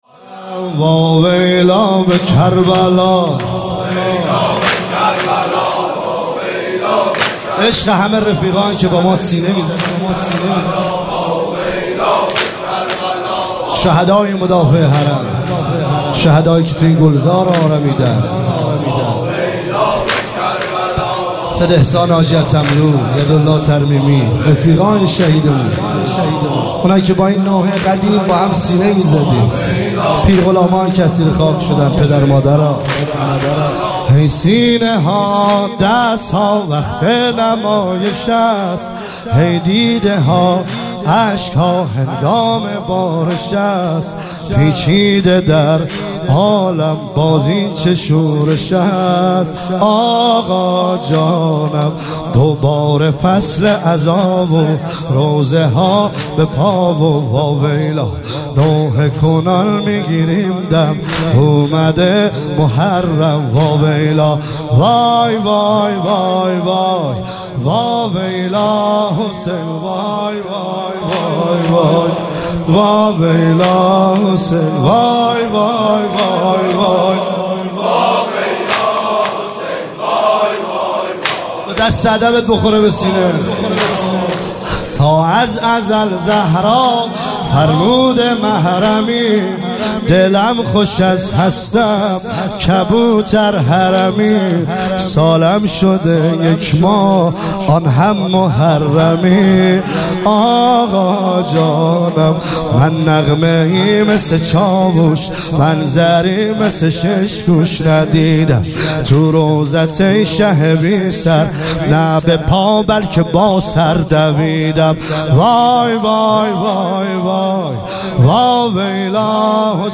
عنوان شب اول محرم الحرام ۱۳۹۸
مداح
برگزار کننده هیئت حسین جان علیه السلام گرگان
واحد تند